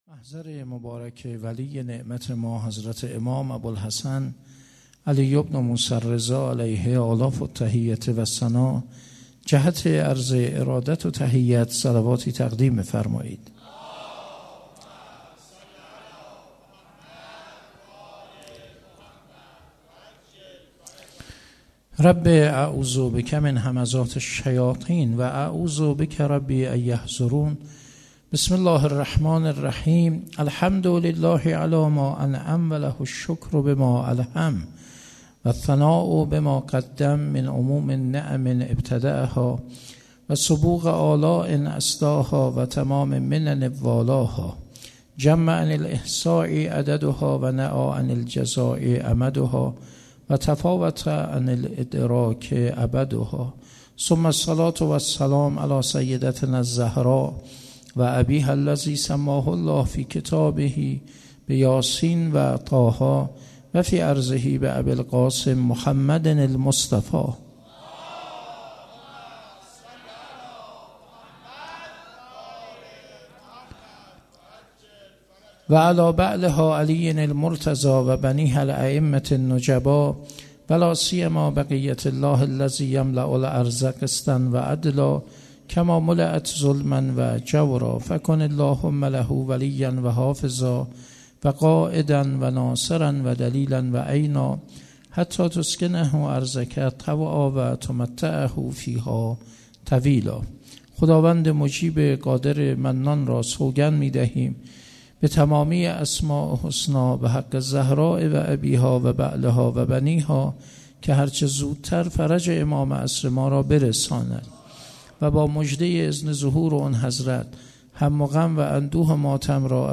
10 بهمن 97 - حسینیه کربلایی ها - سخنرانی